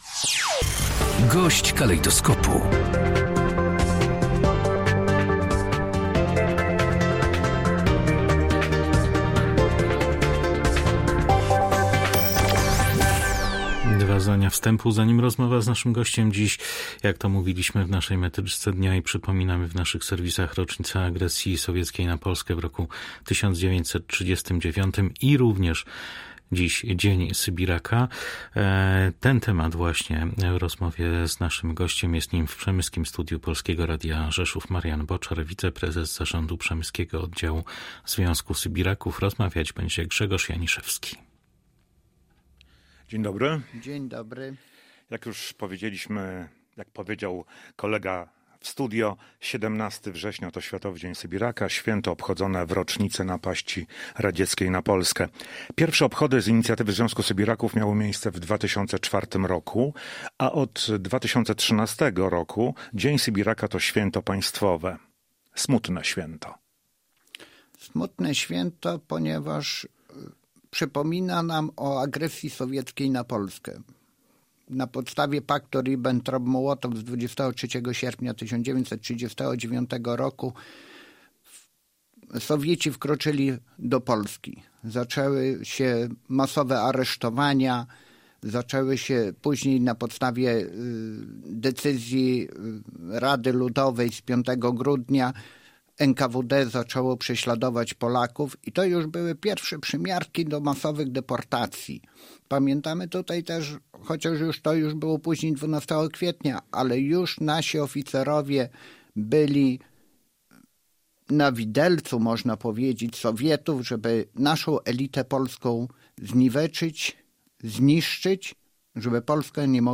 Z gościem dnia rozmawia